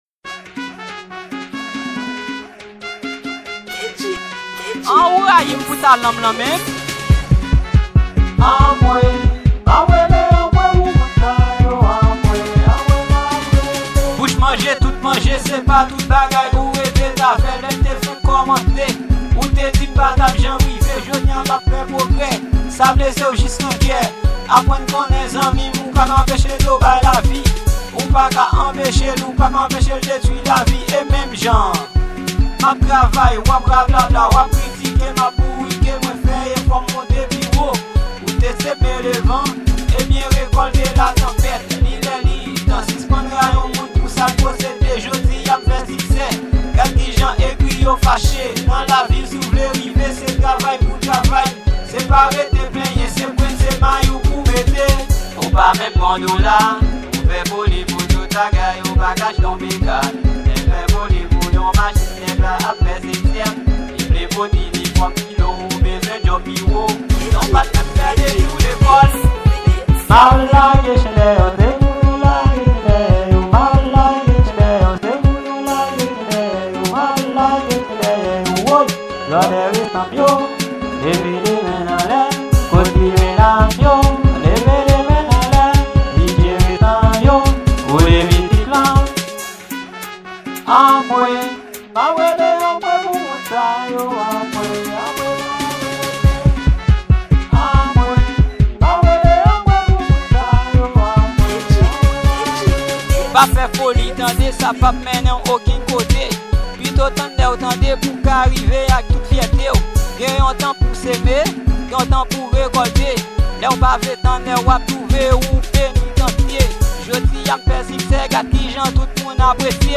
Genre: RADODAY.